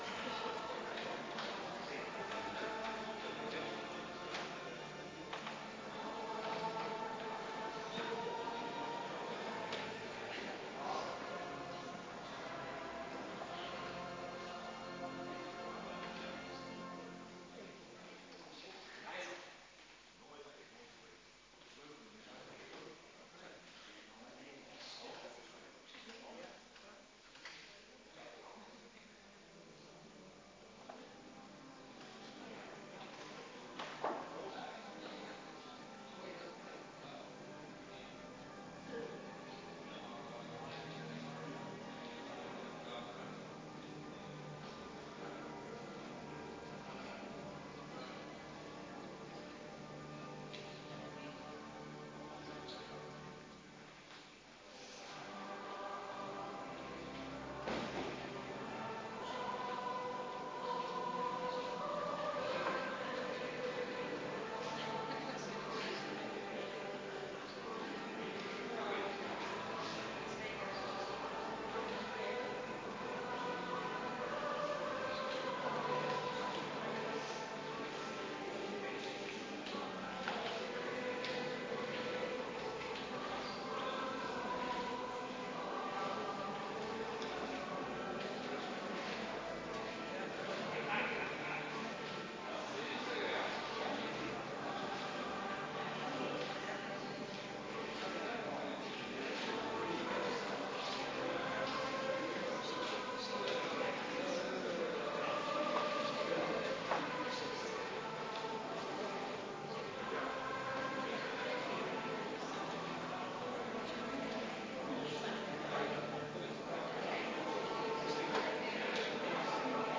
Kerkdiensten
Viering Heilig Avondmaal